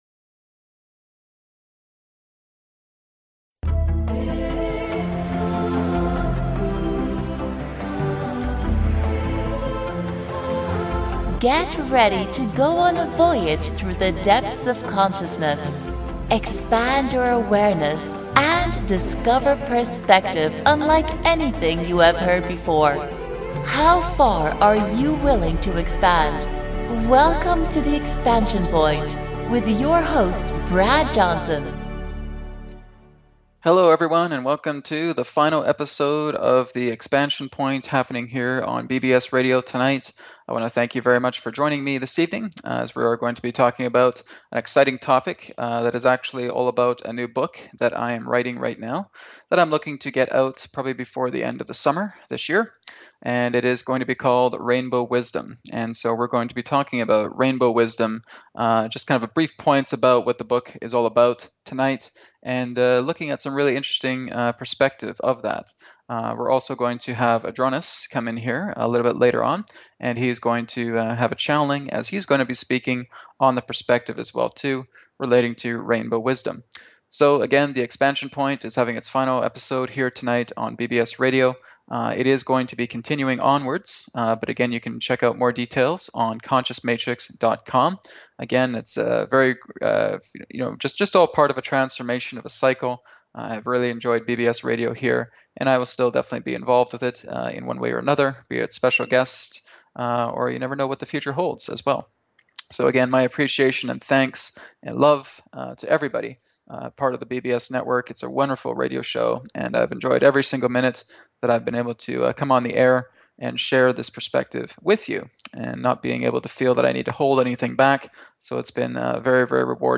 Talk Show Episode, Audio Podcast
Be a part of a radio talk show that’s truly one of a kind and will help you to reach your point of expansion!